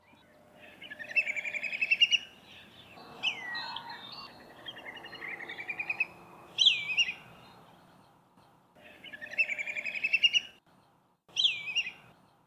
The distinct whistling sound said Whistling Kite (Haliastur sphrenurus).
These kites frequently call to each other when in flight and when perched, so are more often located by sound rather than by sight.
Whistling Kite’s call:
whistling-kite.mp3